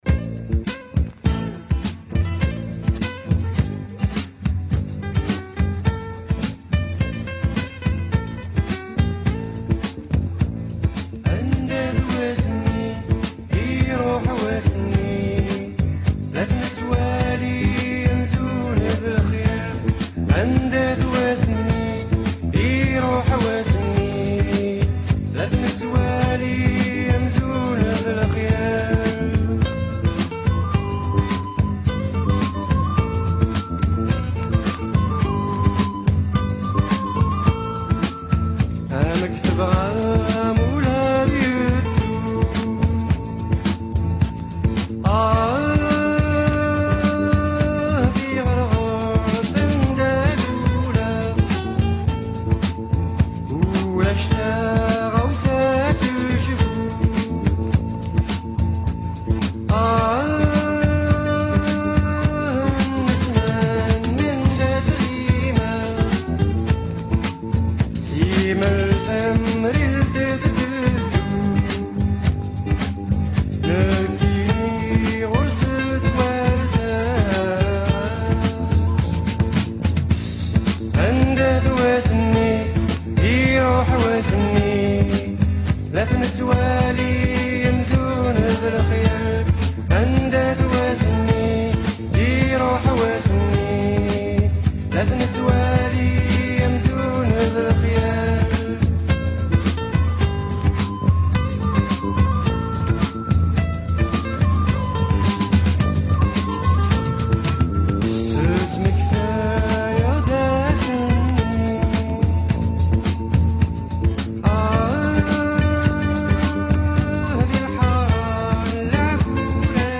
kind of Kabyle / Algerian funk album